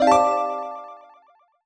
jingle_chime_07_positive.wav